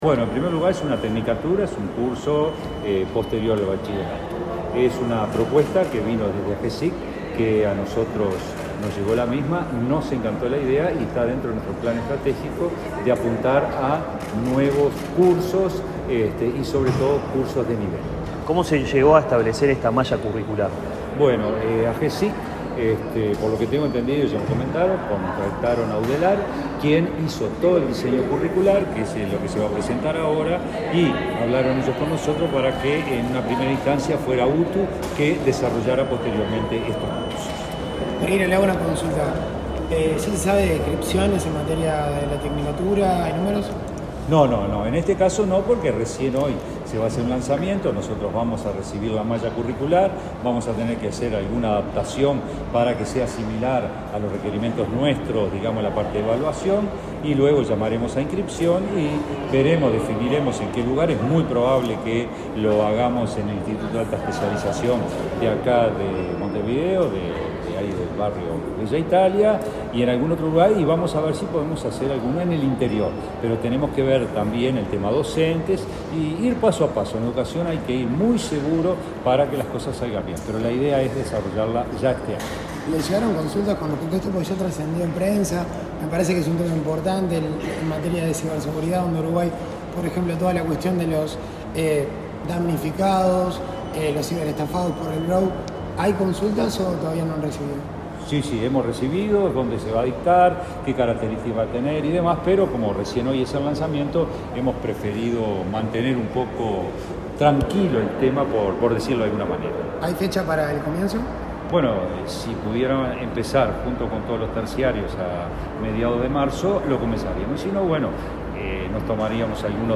Declaraciones del director general de UTU
El director general de UTU, Juan Pereyra, dialogó con la prensa luego de presentar junto con Agesic, la currícula técnica en ciberseguridad,